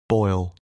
8. boil (v.) /bɔil/ sôi, luộc